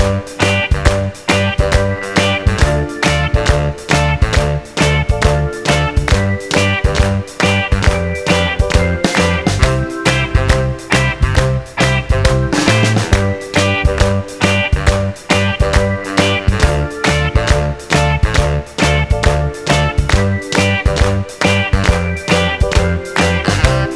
With Chord No Backing Vocals Soul / Motown 2:25 Buy £1.50